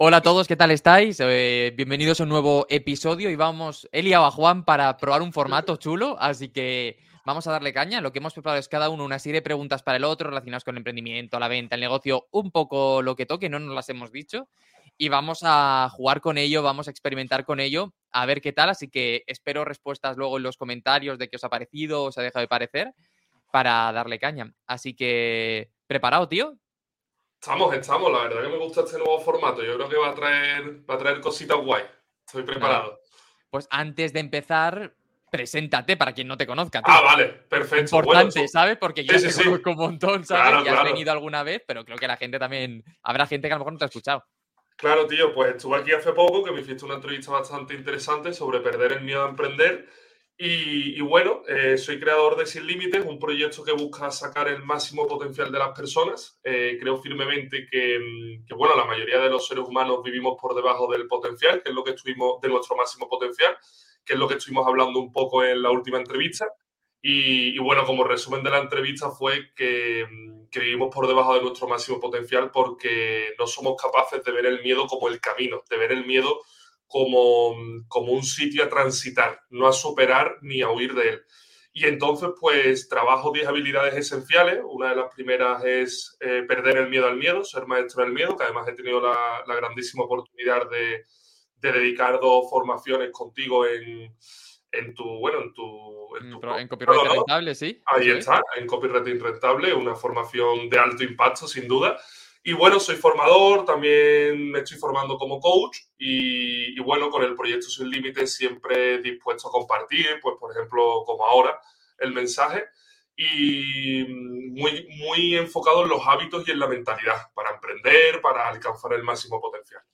Un VS. entre dos emprendedores que se lanzan preguntas al cuello.